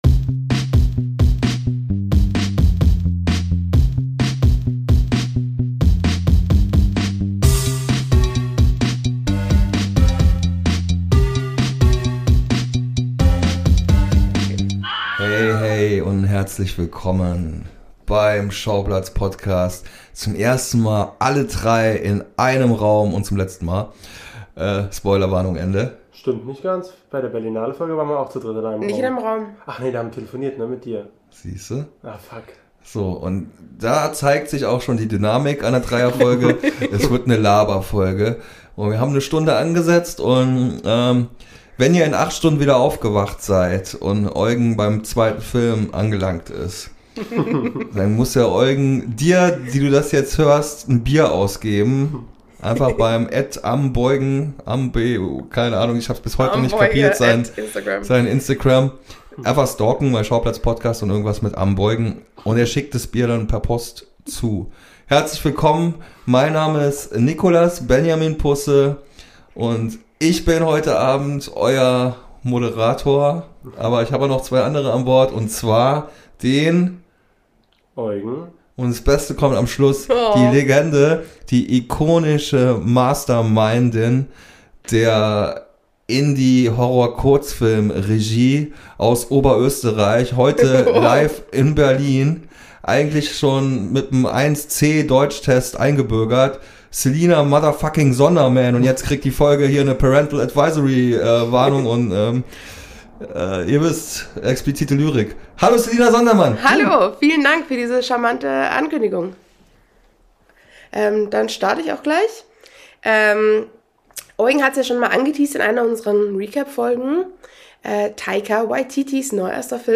Als unvorstellbar tolles Abschiedsgeschenk konnten keine Geringeren als Sofia Coppola, Taika Waititi und Alexander Scheer vor die Mikros gezerrt werden und kommen in dieser Episode jeweils kurz zu Wort!